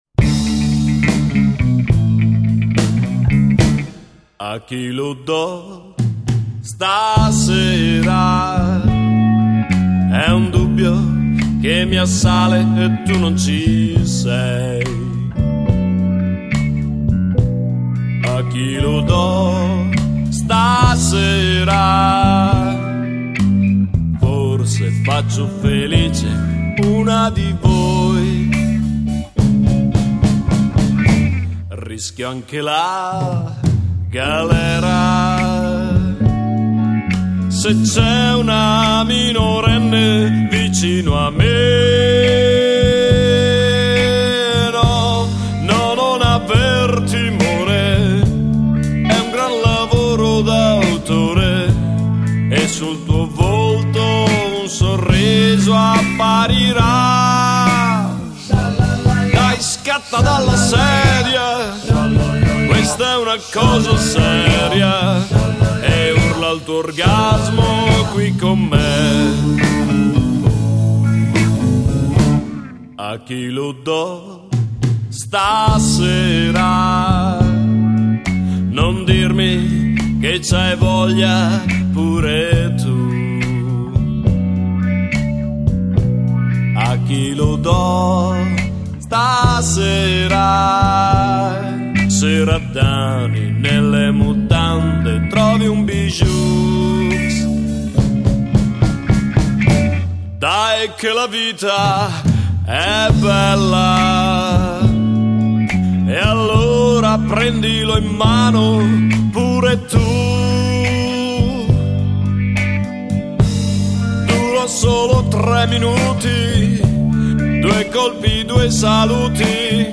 Musica Demenziale e altro...